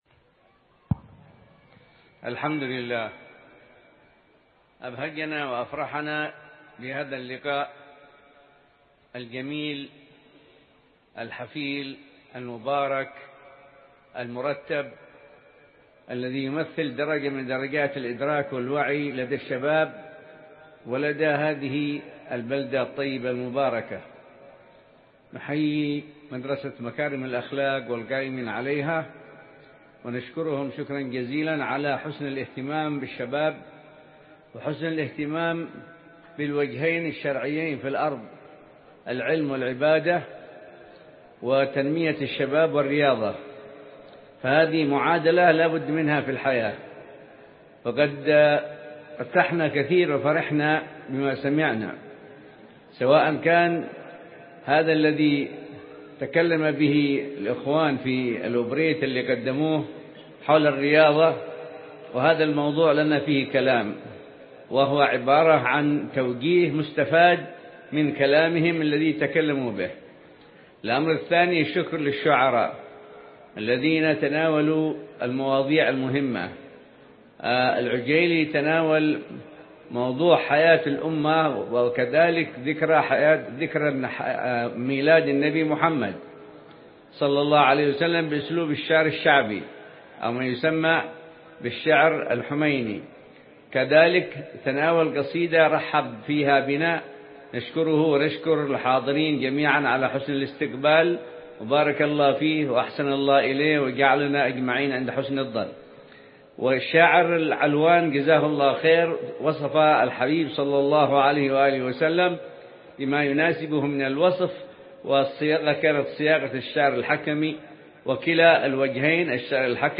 كلمة
في مدرسة مكارم الأخلاق بالريضة – حضرموت